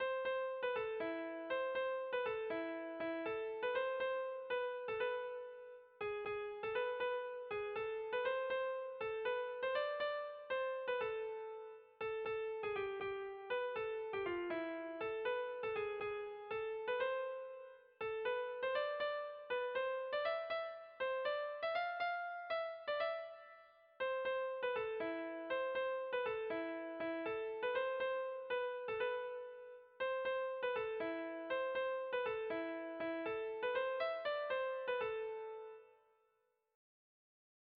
Bertsolari
Hamarreko handia (hg) / Bost puntuko handia (ip)
A-B-C-D-A